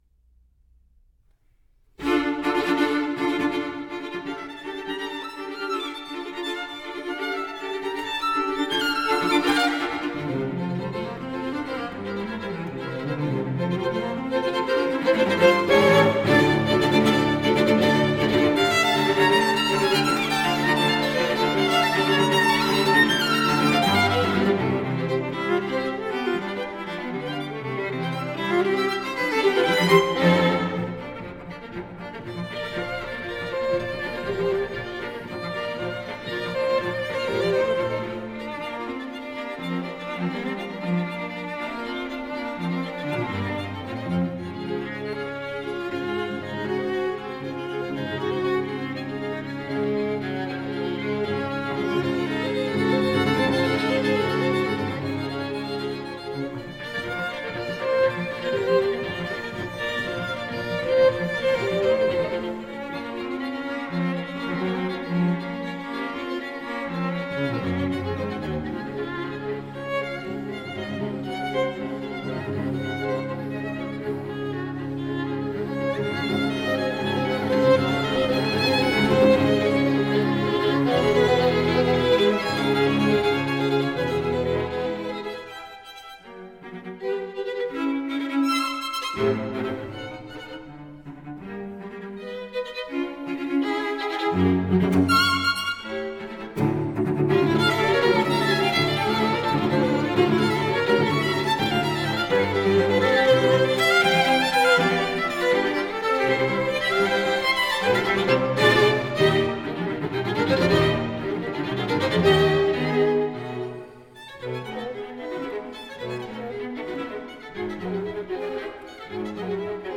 Felix Weingartener (1863-1942): String Quartet no. 3 op. 34 in F major. II. Allegro molto.
Sarastro Quartet